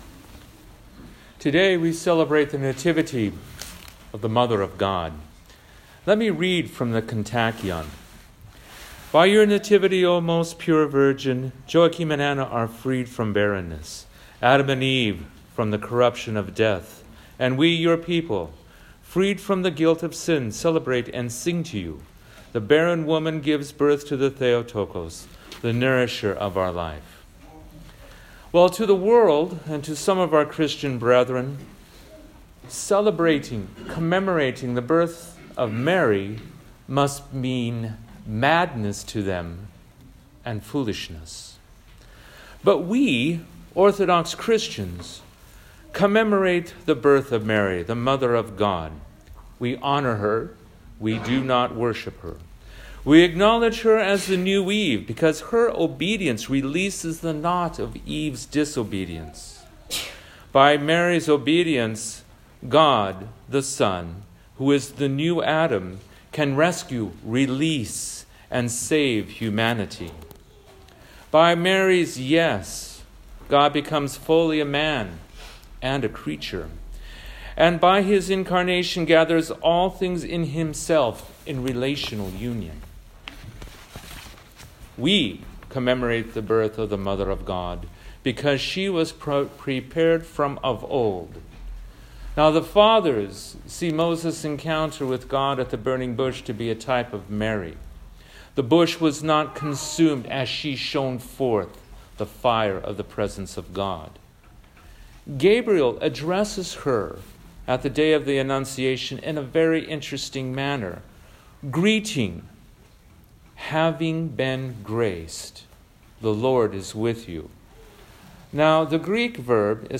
The following is a homily which corresponds to this posting: